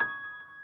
piano24.ogg